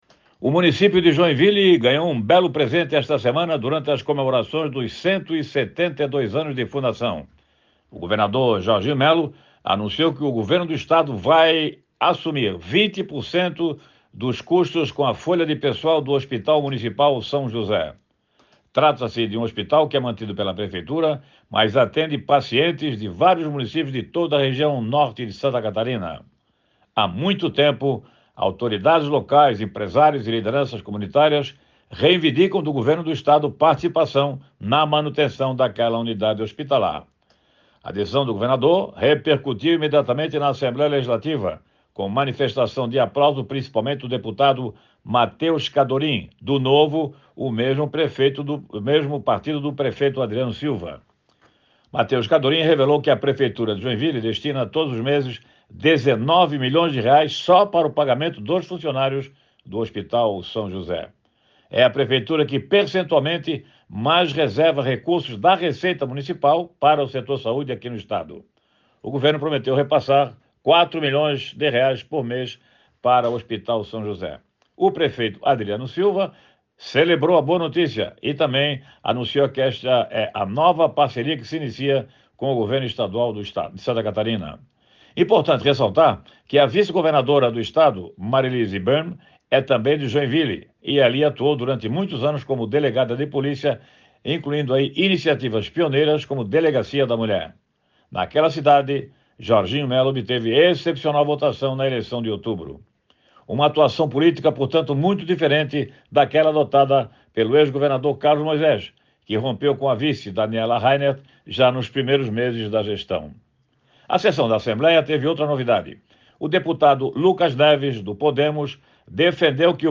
Jornalista comenta anúncio feito pelo governador Jorginho Mello nas comemorações dos 172 anos de fundação da cidade do Norte do Estado